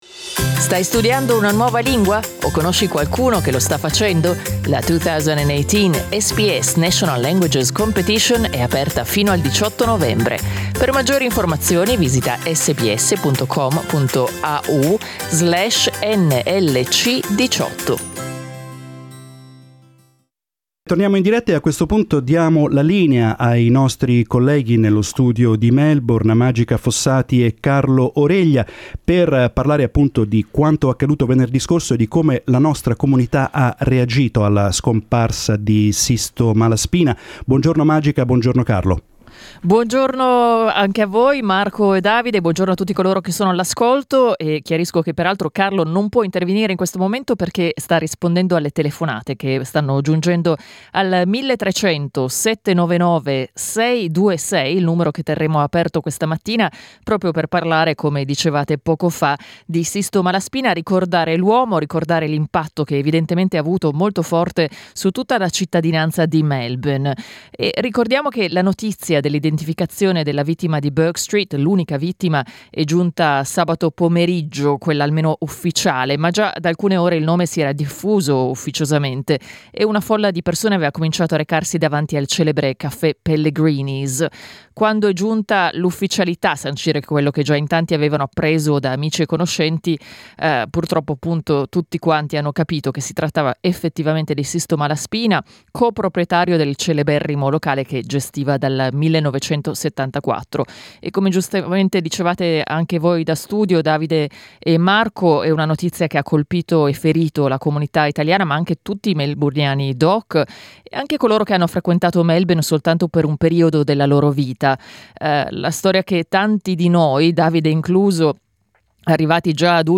noi questa mattina abbiamo aperto le linee per ricordarlo con voi.